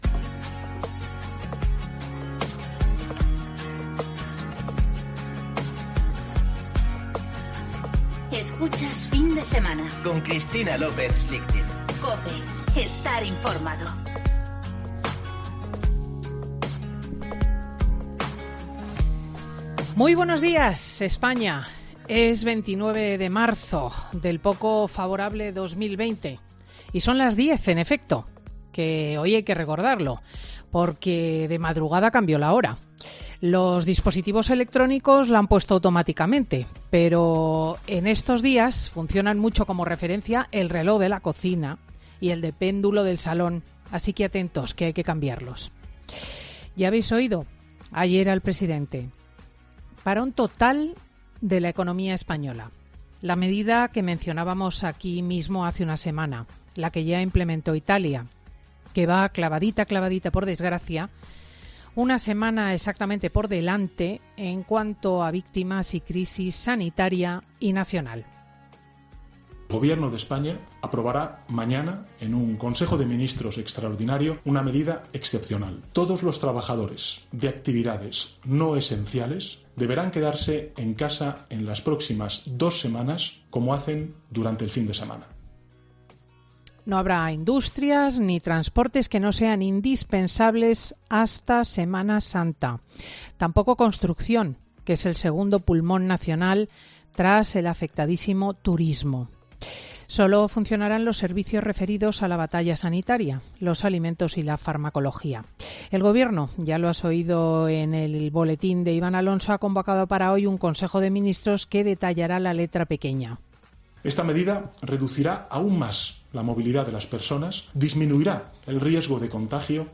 12.16 | FIN DE SEMANA  Escucha de nuevo el monólogo de Cristina L. Schlichting: "Sánchez no ha avisado ni a empresarios ni a oposición ¿Por qué está torpeza?” PINCHA AQUÍ